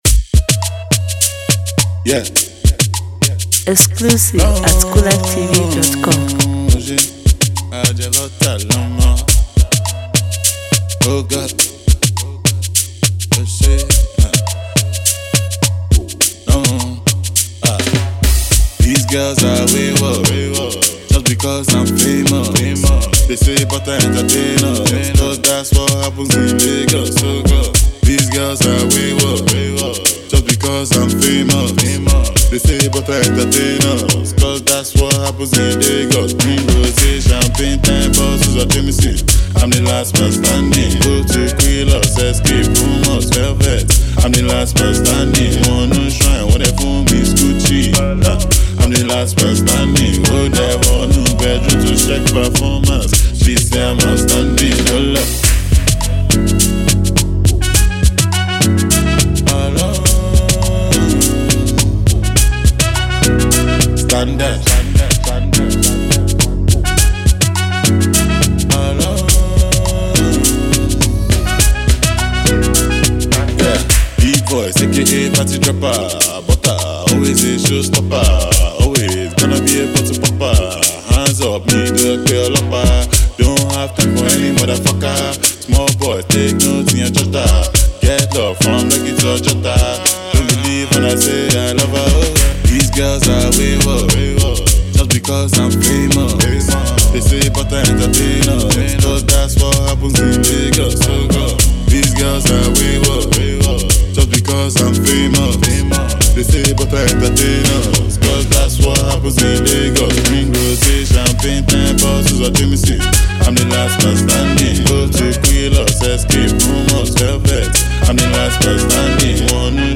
light and exciting new single